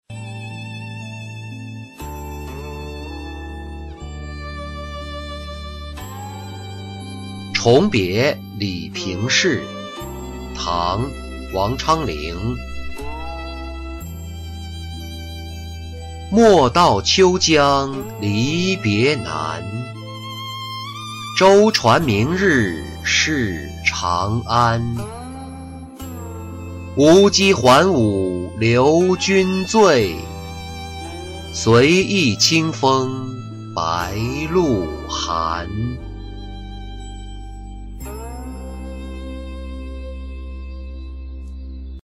重别李评事-音频朗读